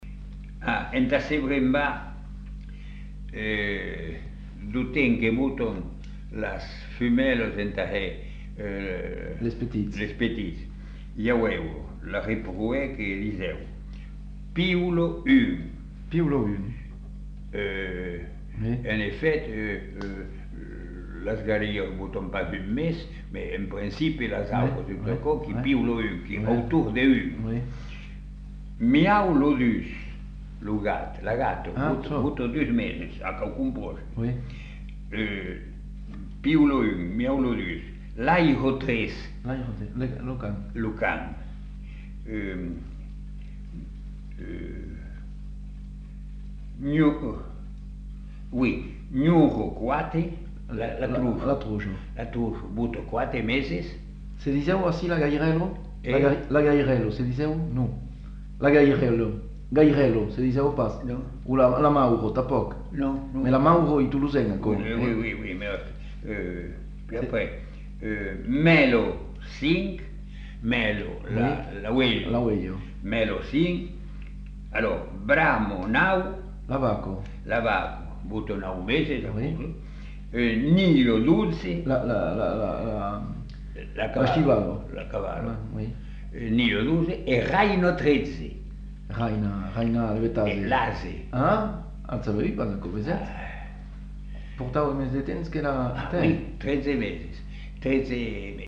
Lieu : Masseube
Genre : forme brève
Effectif : 1
Type de voix : voix d'homme
Production du son : récité
Classification : formulette